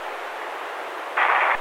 爆炸（一般）
描述：Sonido de una爆炸将军
标签： 战斗 爆炸 样品
声道立体声